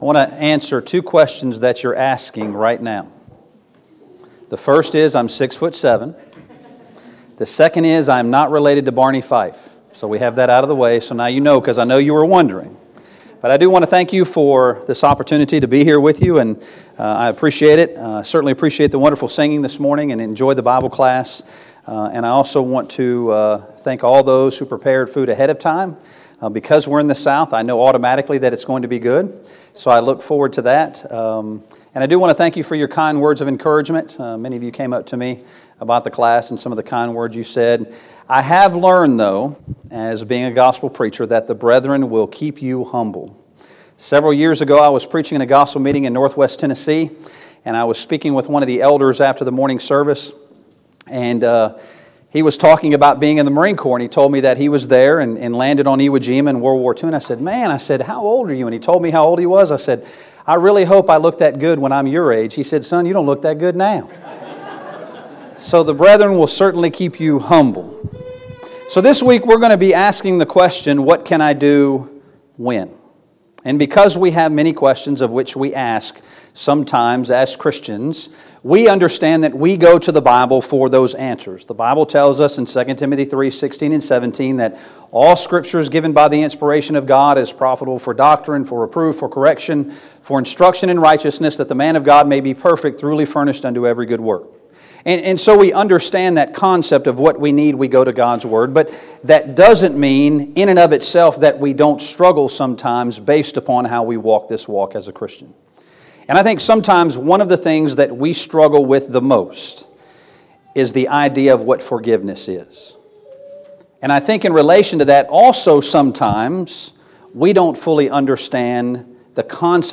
2019 Spring Gospel Meeting Service Type: Gospel Meeting Preacher